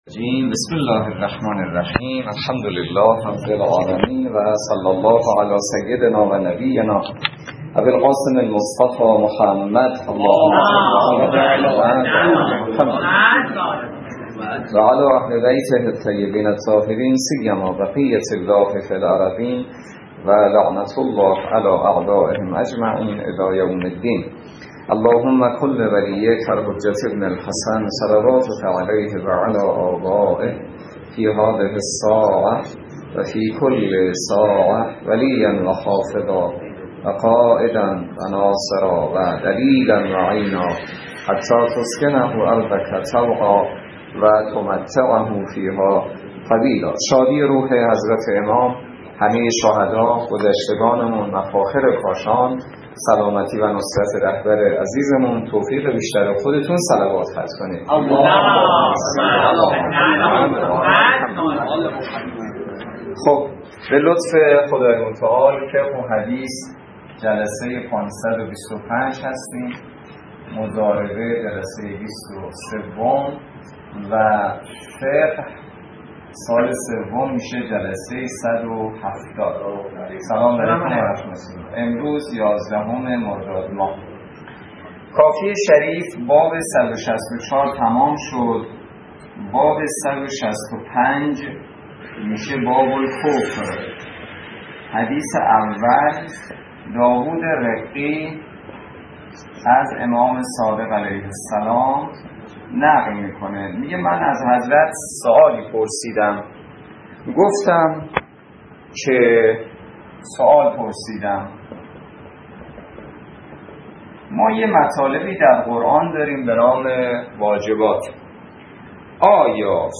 روایات ابتدای درس فقه موضوع: فقه اجاره - جلسه ۲۳